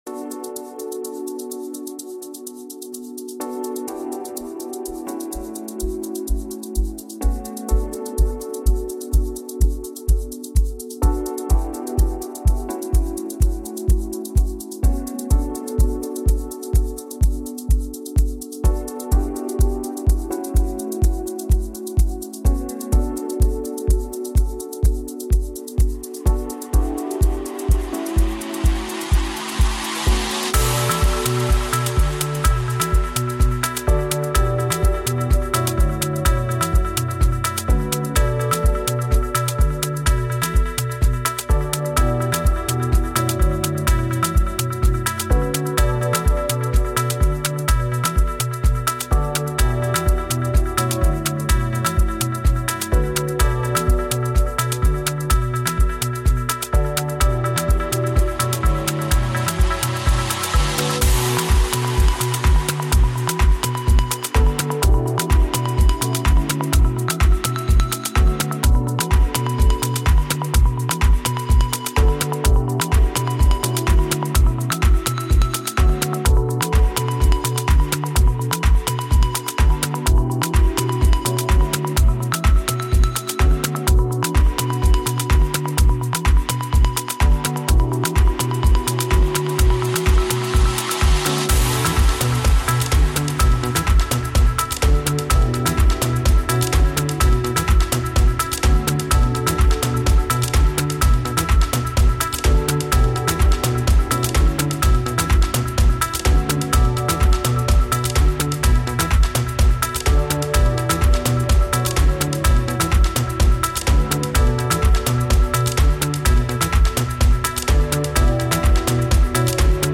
Musique dynamique libre de droit pour vos projets.